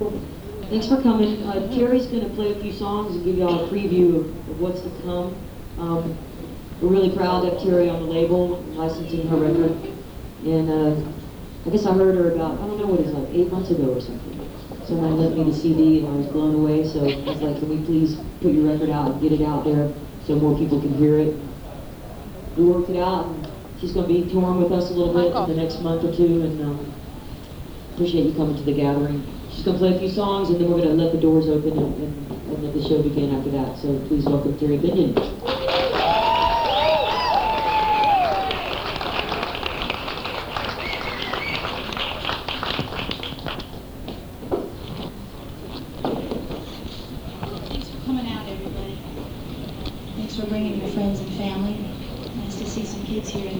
lifeblood: bootlegs: 1997: 1997-10-10: the freight room - decatur, georgia